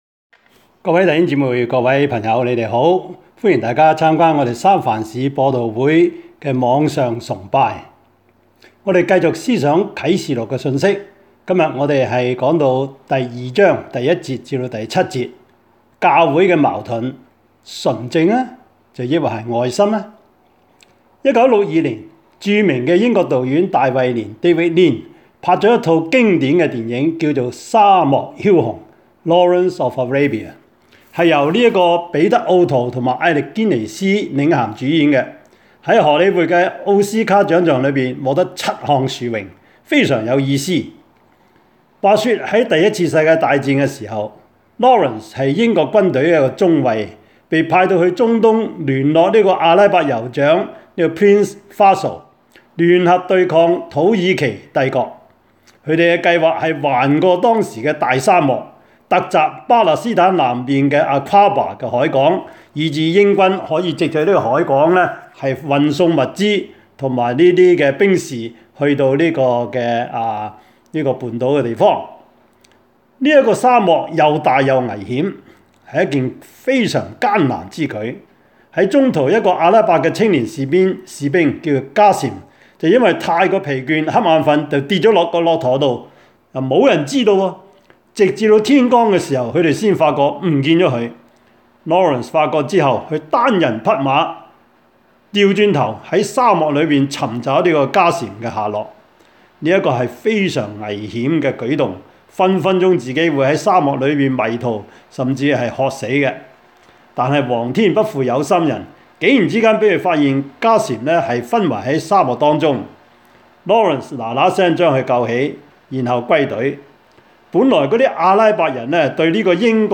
Topics: 主日證道 « 合作?